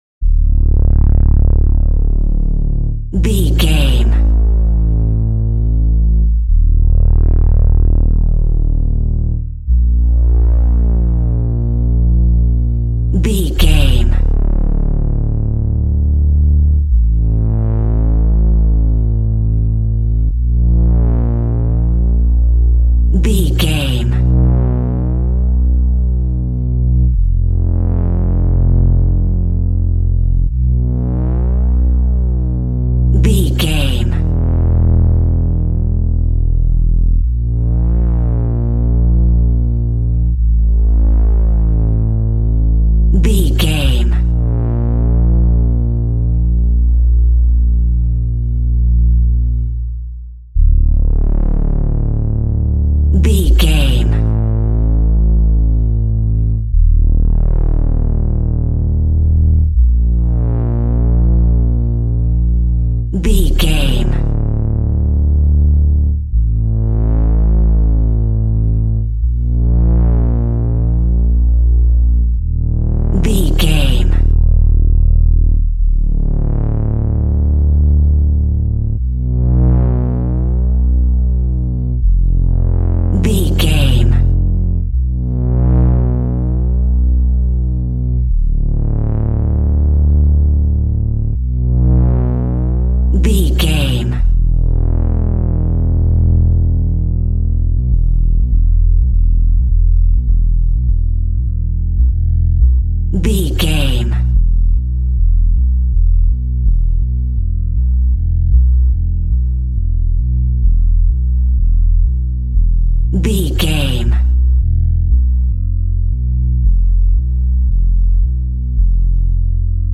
In-crescendo
Thriller
Aeolian/Minor
ominous
suspense
eerie
Horror synth
Horror Ambience
electronics
synthesizer